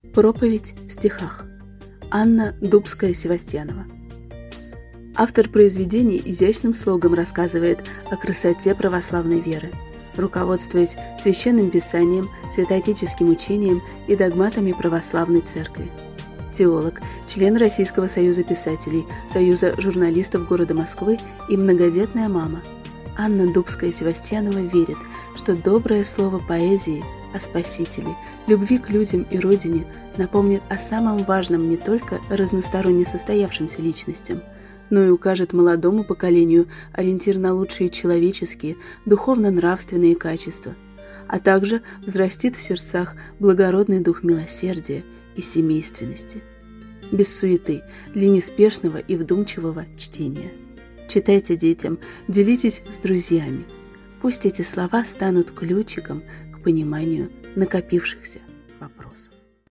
Аудиокнига Проповедь в стихах | Библиотека аудиокниг